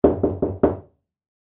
Звуки стуков в дверь
Стук в дверь снаружи